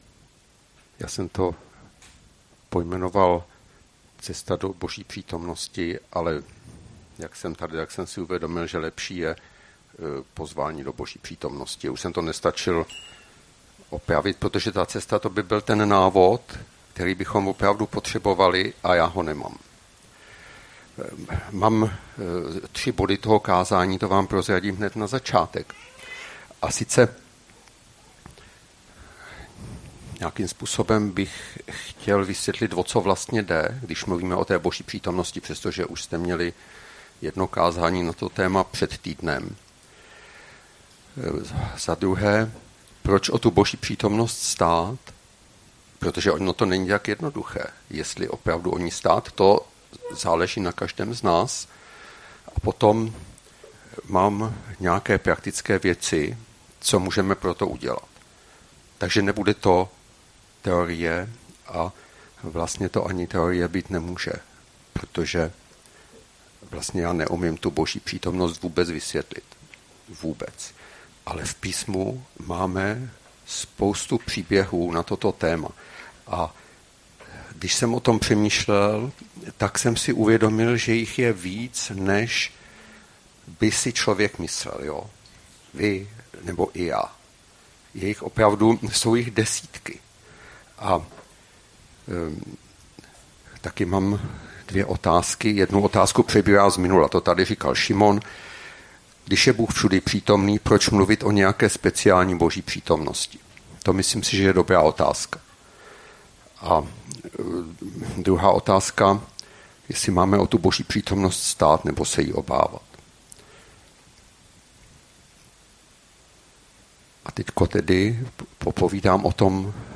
Kázání - Strana 28 z 220 - KS Praha